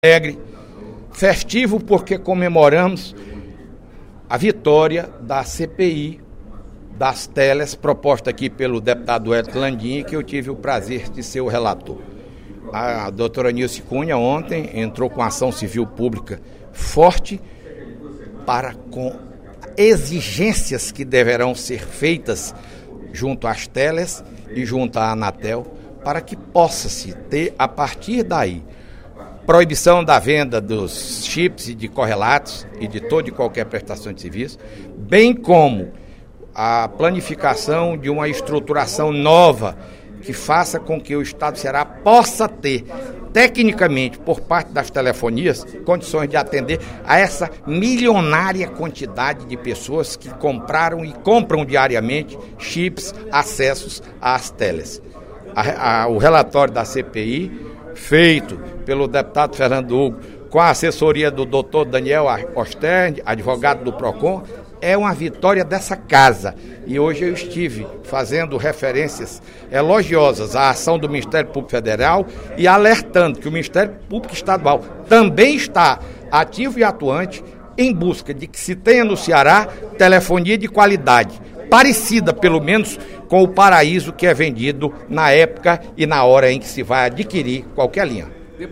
O deputado Fernando Hugo (SD) avaliou, em pronunciamento no primeiro expediente da sessão plenária desta sexta-feira (05/12), a ação civil pública do Ministério Público que pediu à Justiça o cancelamento de novos planos de telefonia móvel no Ceará.